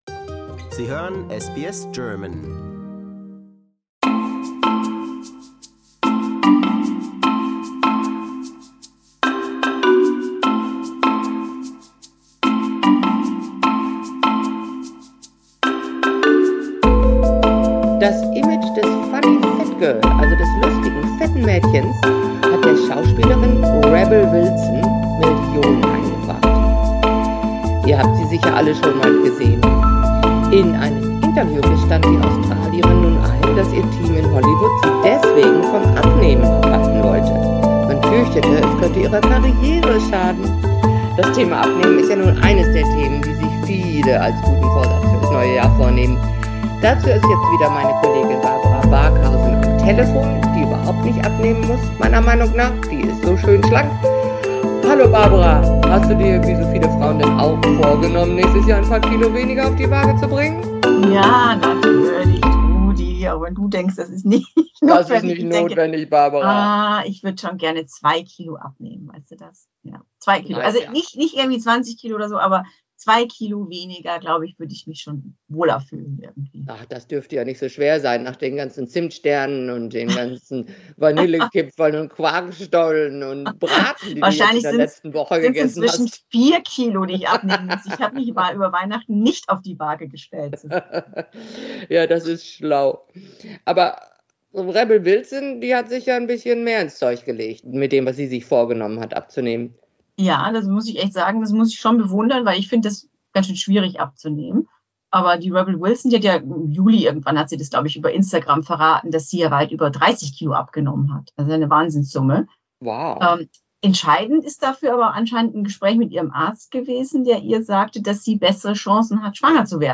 am Telefon.